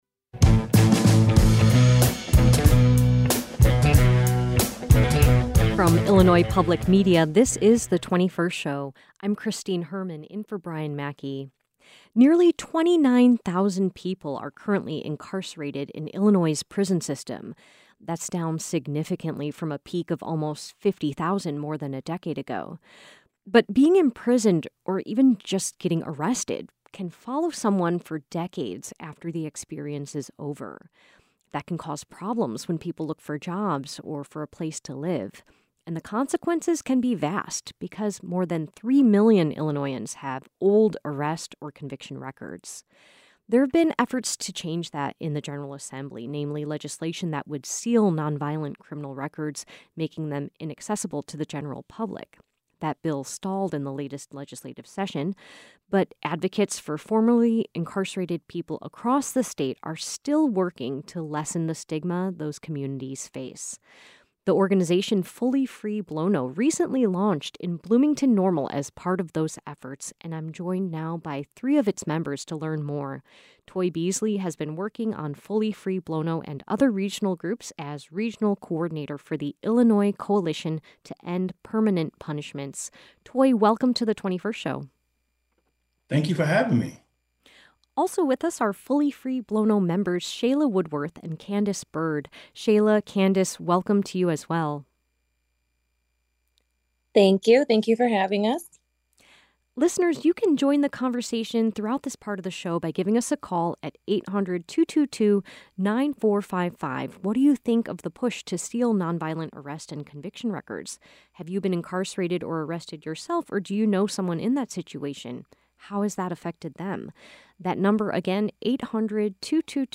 Fully Free Blo-No, a recently-launched organization in Blooming-Normal, is part of that effort and three of its members join the program today.